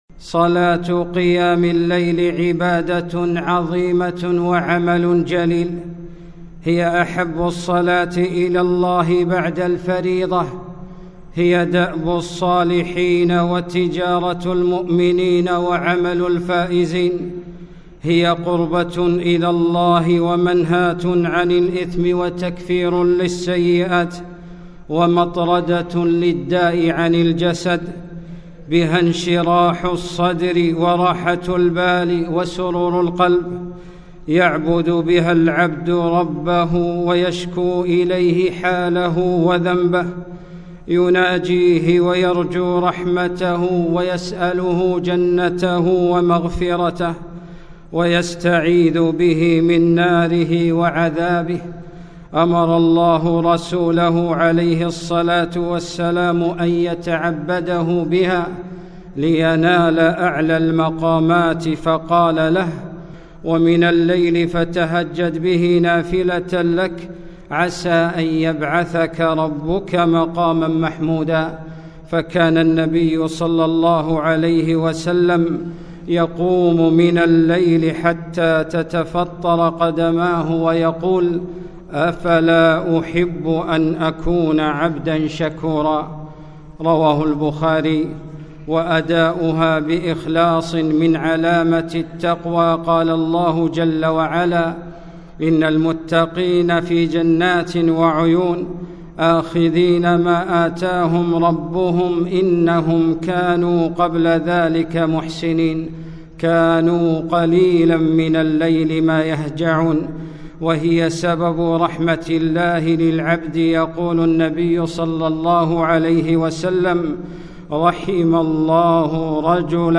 خطبة - صلاة قيام الليل